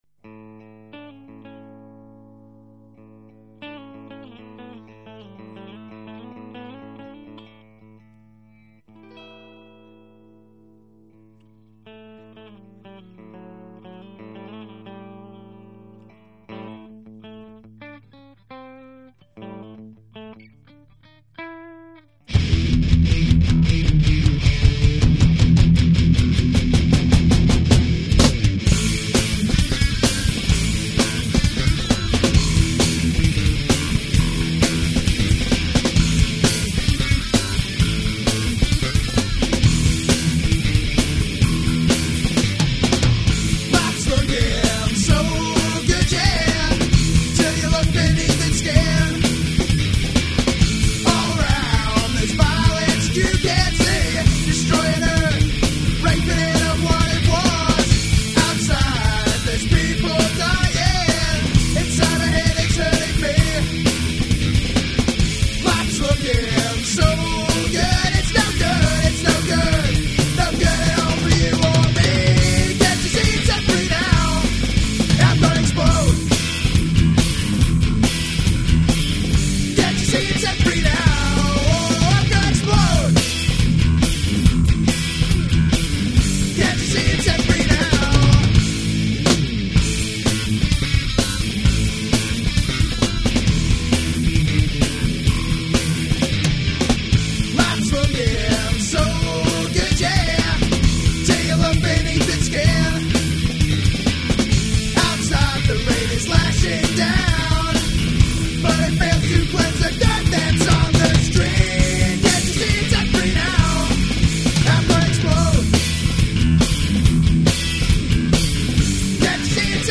----Funk Rock Psycadelia----